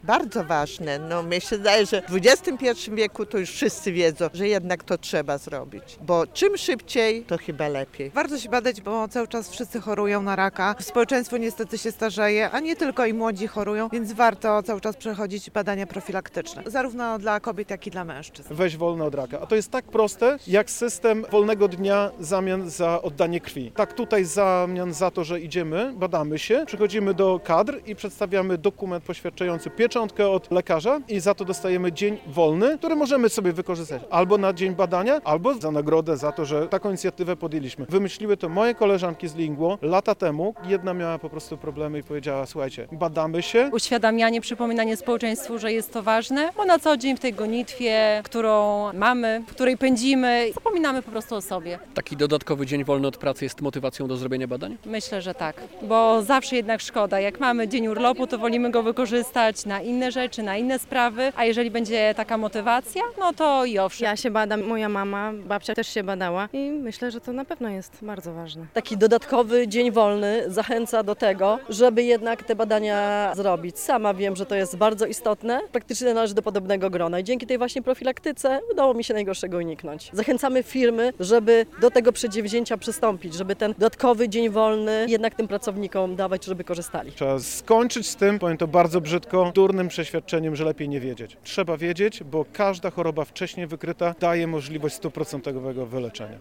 relacja
We wtorek (29.10) członkinie fundacji L zorganizowały happening na Starym Rynku w Łomży, podczas którego zachęcały do badań profilaktycznych.